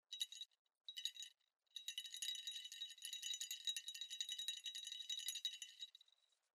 Original sound recordings of pellet bells from Avar Age cemetery Komárno IX (Lodenica cemetery), graves 65, 86, 107.
original sound of pellet bell A5813d, grave 107 0.1 MB